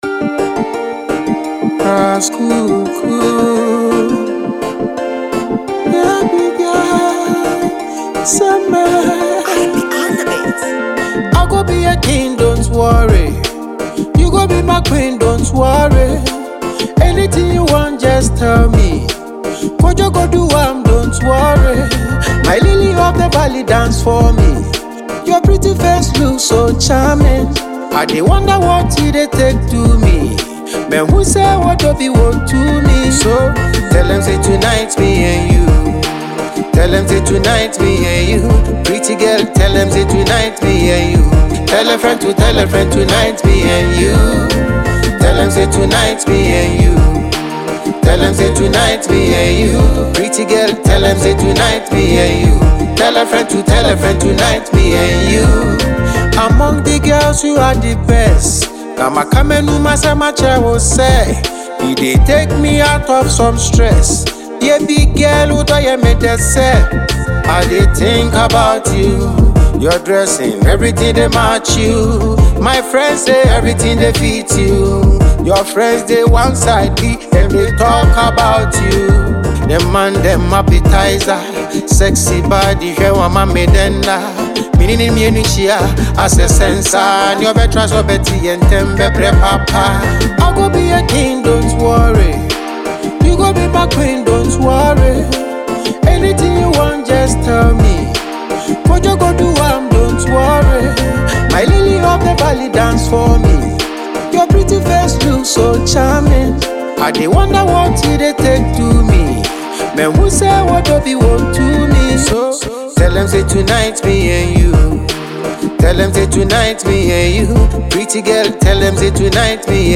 known for his forceful delivery
with its superb production and engaging melodies